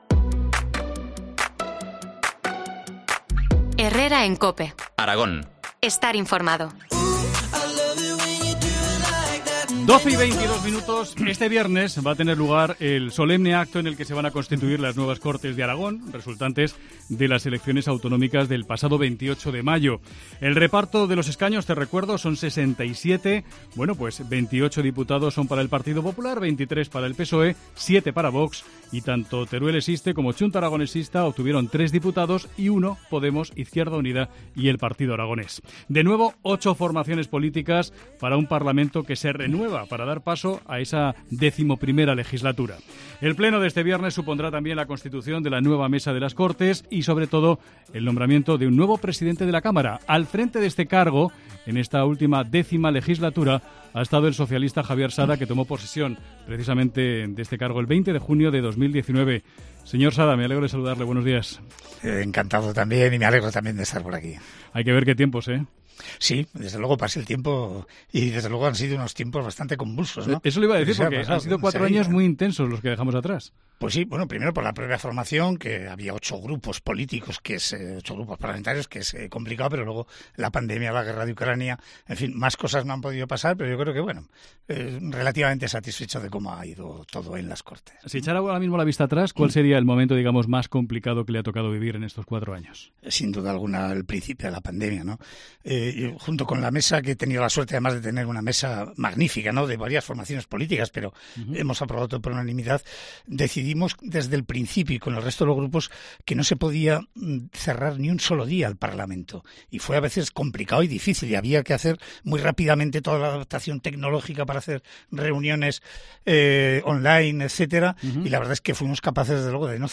Entrevista a Javier Sada, presidente de las Cortes de Aragón en la X Legislatura.